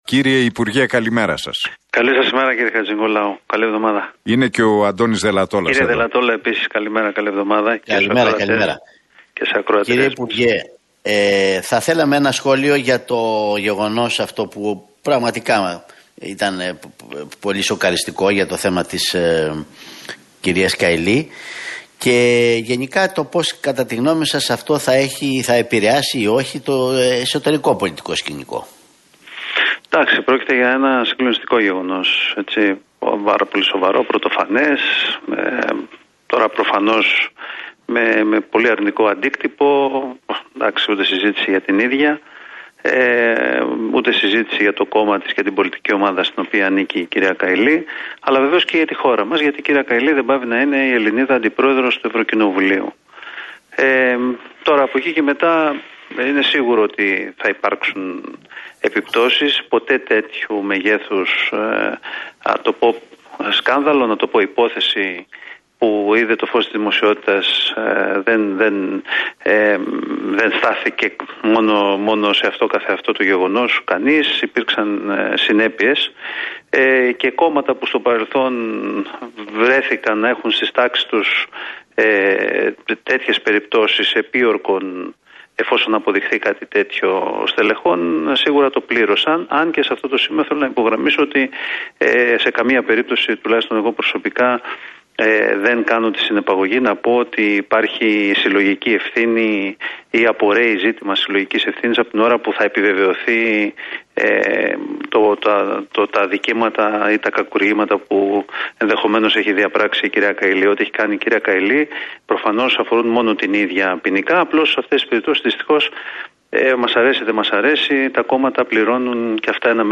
Ο κοινοβουλευτικός εκπρόσωπος του ΣΥΡΙΖΑ, Γιάννης Ραγκούσης δήλωσε στον Realfm 97,8